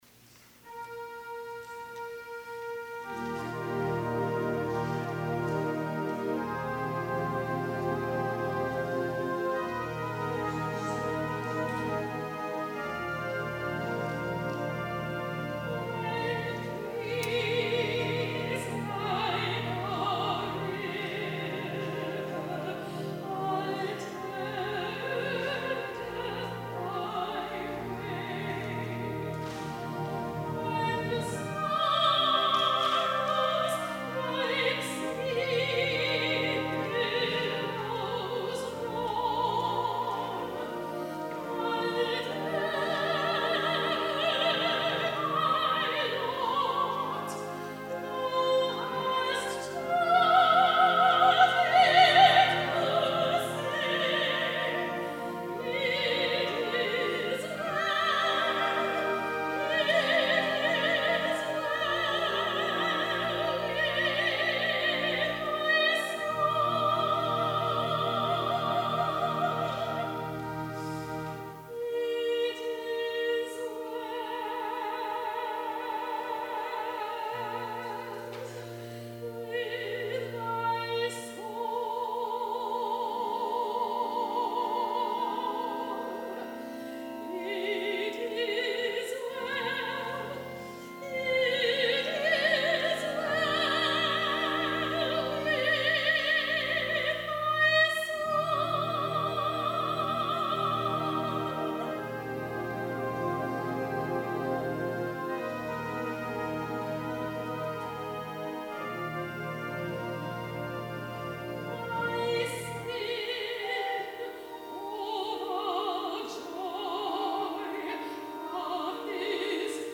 SOLO It Is Well with My Soul
soprano